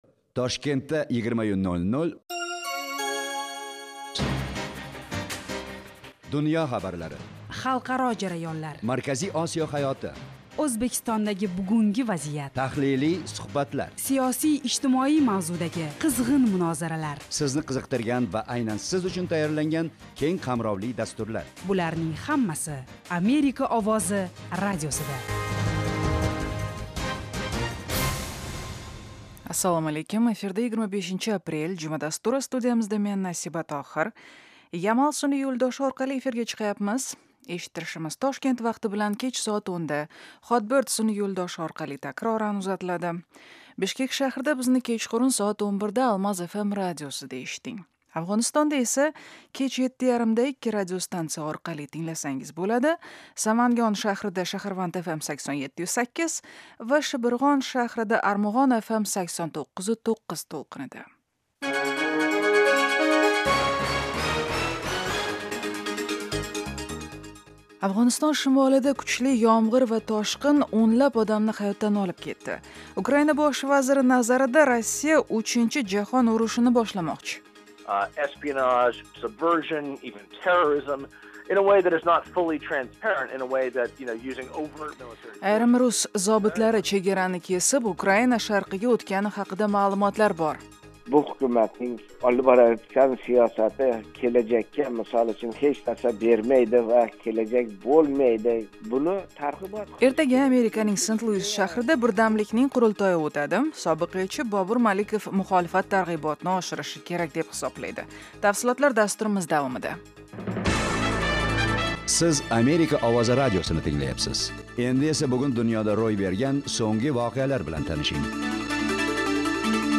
Toshkent vaqti bilan har kuni 20:00 da efirga uzatiladigan 30 daqiqali radio dastur kunning dolzarb mavzularini yoritadi. O'zbekiston va butun Markaziy Osiyodagi o'zgarishlarni tahlil qiladi. Amerika bilan aloqalar hamda bu davlat siyosati va hayot haqida hikoya qiladi.